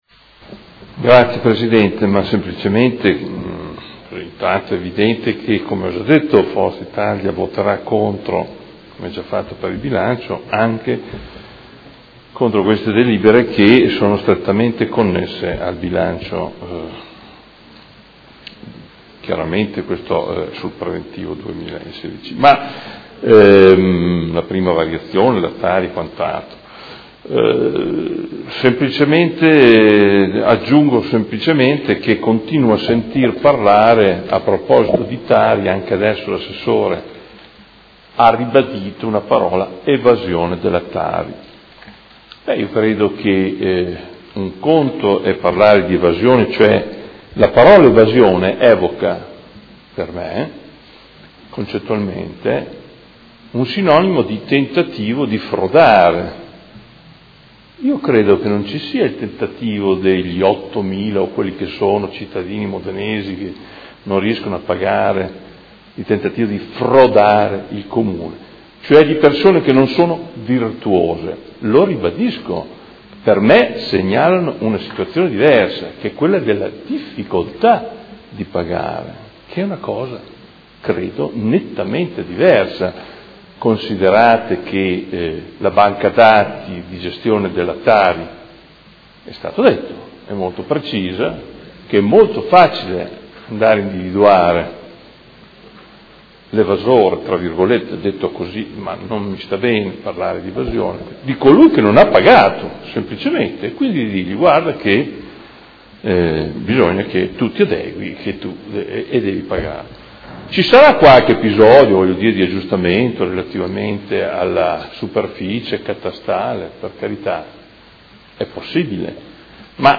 Adolfo Morandi — Sito Audio Consiglio Comunale
Dichiarazioni di voto